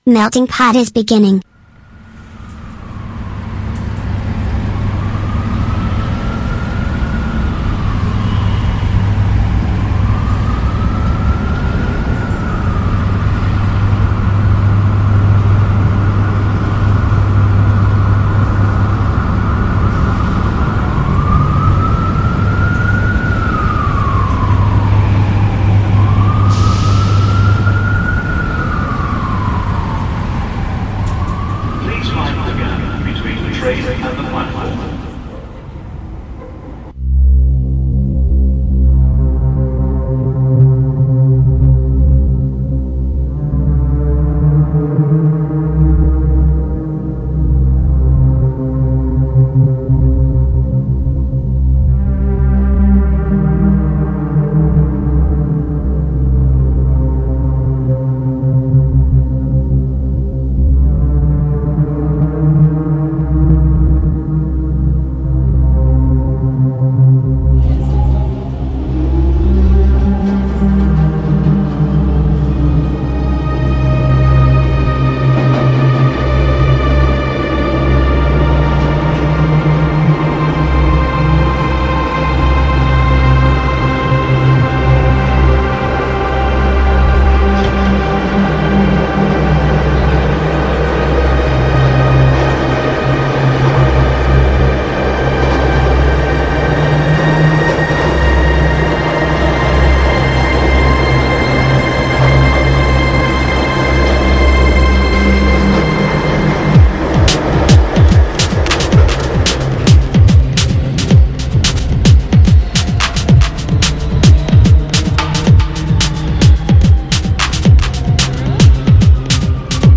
All’interno del podcast i dischi d’esordio con il botto, con il tributo iniziale alla musica trip hop.